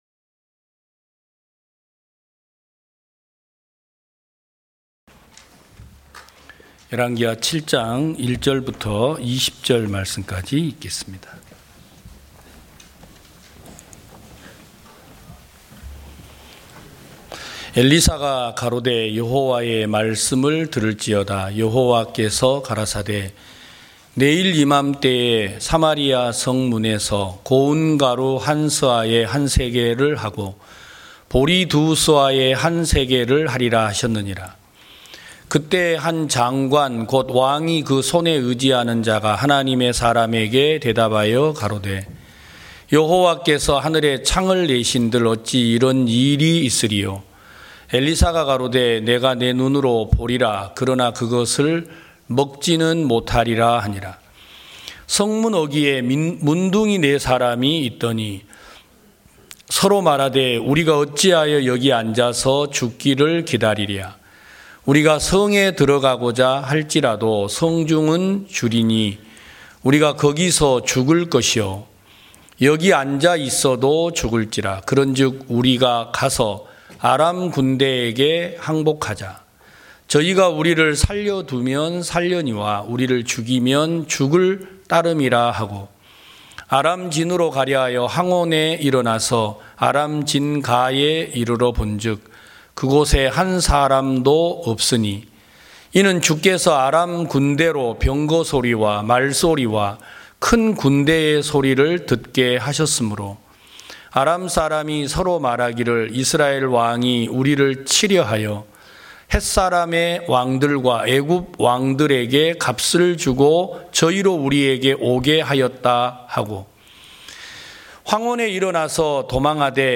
2023년 1월 1일 기쁜소식부산대연교회 주일오전예배
성도들이 모두 교회에 모여 말씀을 듣는 주일 예배의 설교는, 한 주간 우리 마음을 채웠던 생각을 내려두고 하나님의 말씀으로 가득 채우는 시간입니다.